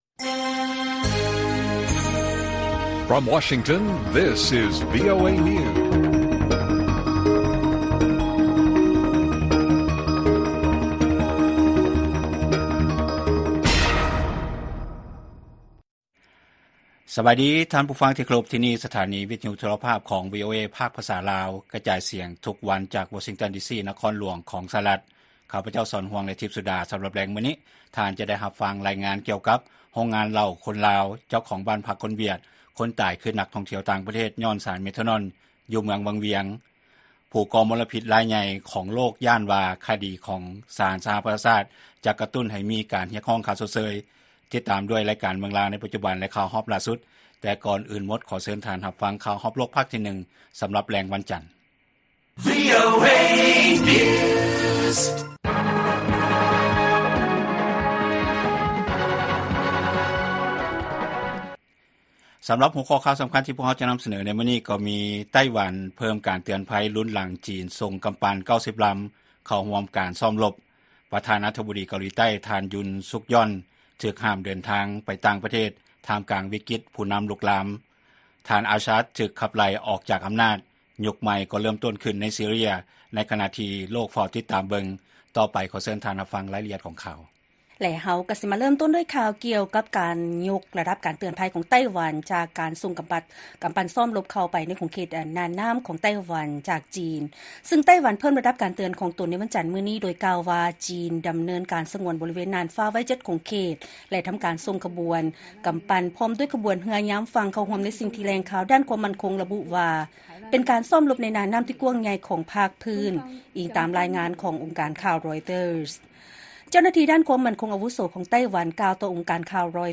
ວີໂອເອພາກພາສາລາວ ກະຈາຍສຽງທຸກໆວັນ, ຫົວຂໍ້ຂ່າວສໍາຄັນສໍາລັບແລງມື້ນີ້ ມີດັ່ງນີ້: ໄຕ້ຫວັນ ເພີ້ມການເຕືອນໄພ, ປະທານາທິບໍດີເກົາຫຼີໃຕ້ ຖືກຫ້າມເດີນທາງໄປຕ່າງປະເທດ ທ່າມກາງວິກິດຜູ້ນໍາທີ່ລຸກລາມ, ແລະ ເມື່ອ ທ່ານ ອາຊາດ ຖືກຂັບໄລ່ອອກຈາກອໍານາດ, ຍຸກໃໝ່ກໍເລີ້ມຕົ້ນຂຶ້ນໃນ ຊີເຣຍ ໃນຂະນະທີ່ໂລກເຝົ້າຕິດຕາມເບິ່ງ.